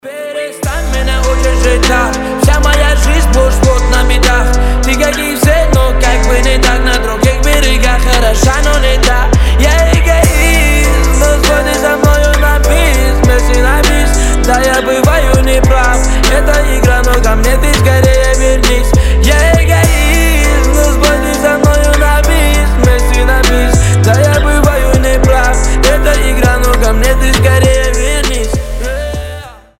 Поп Музыка
грустные # кавказские